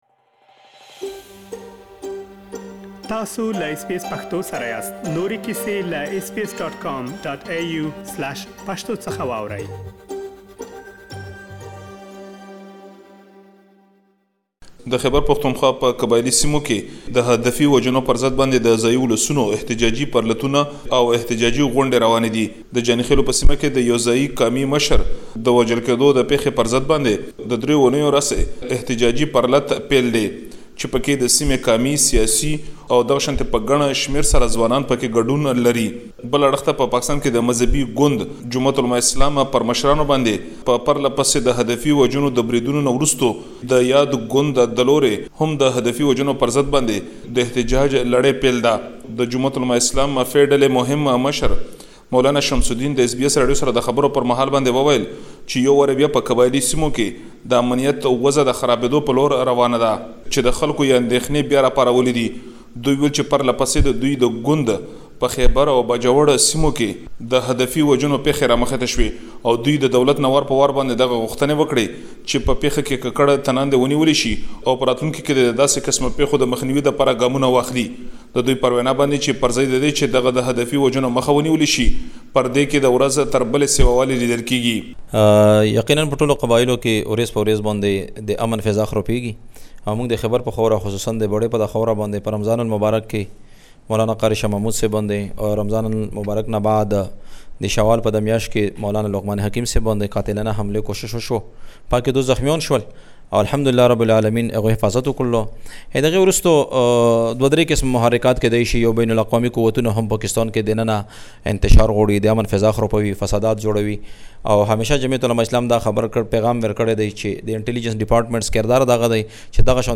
تاسو ته مو د مظاهره کوونکو غږونه راخيستي